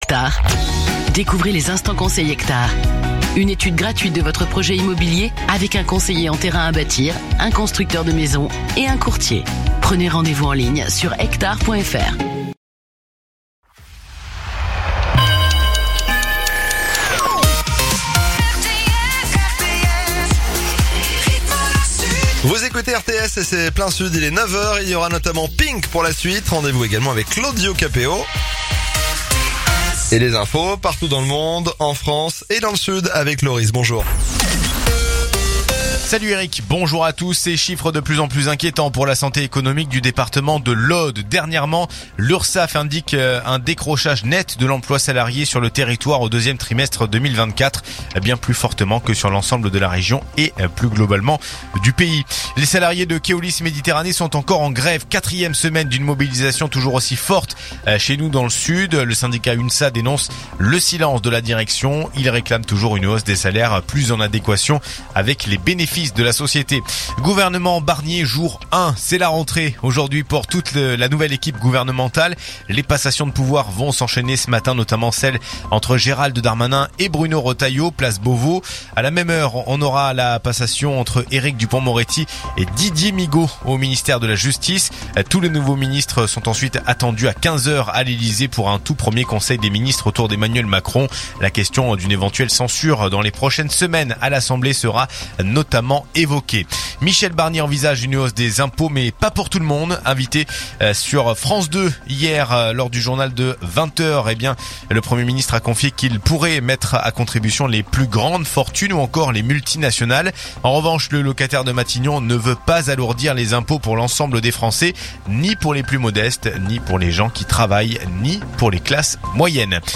Écoutez les dernières actus de Narbonne en 3 min : faits divers, économie, politique, sport, météo. 7h,7h30,8h,8h30,9h,17h,18h,19h.